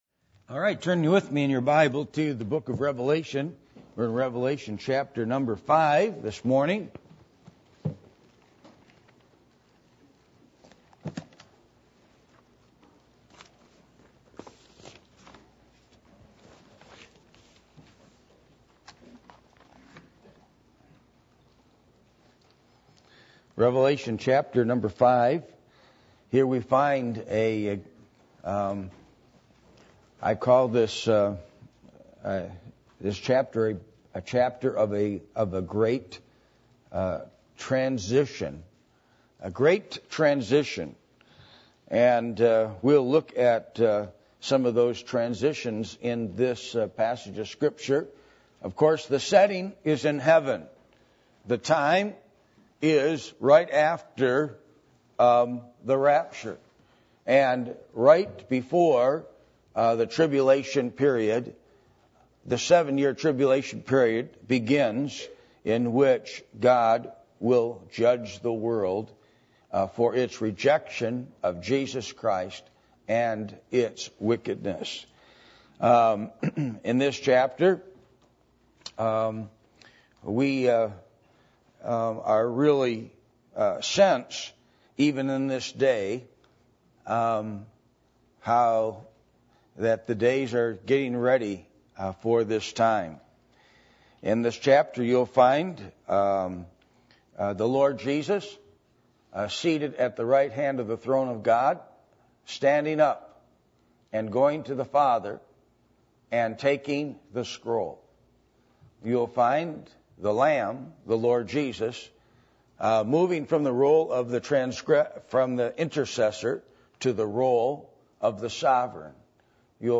Passage: Revelation 5:1-14 Service Type: Sunday Morning